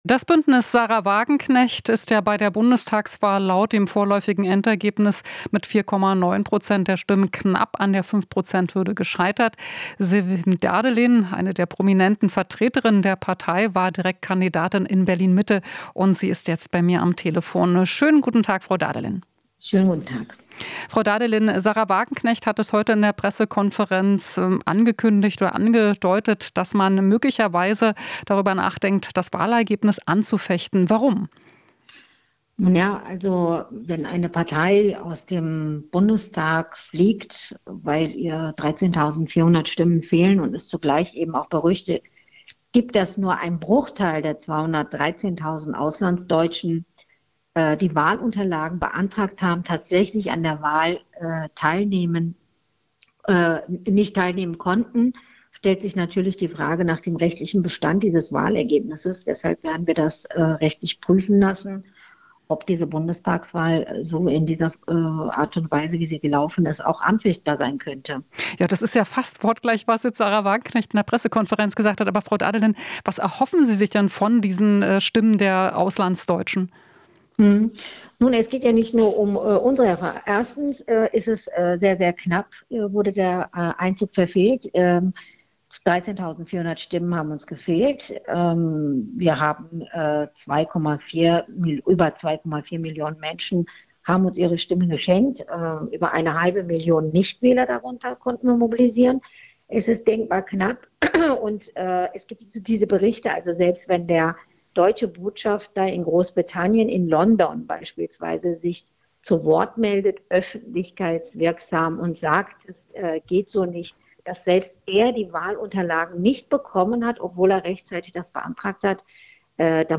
Interview - Dağdelen (BSW): Sollten uns unseren Erfolg nicht schmälern lassen